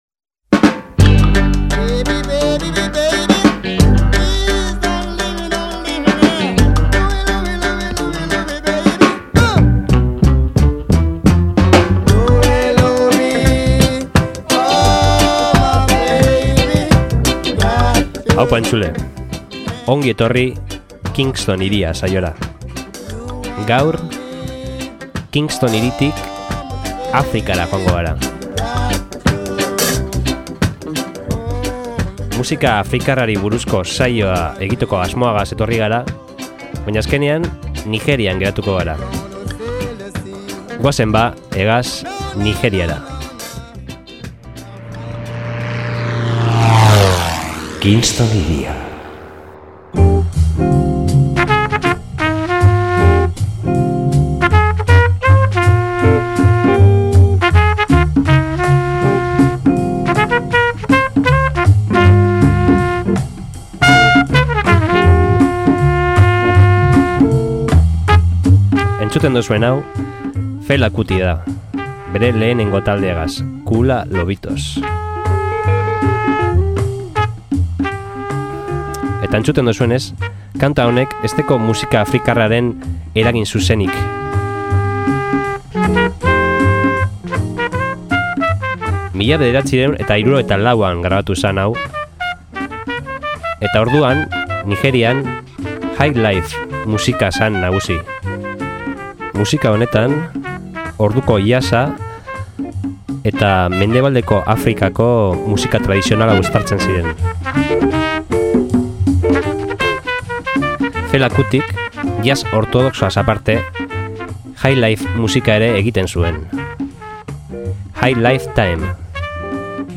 Jazz, Highlife, Funk, Afrobeat…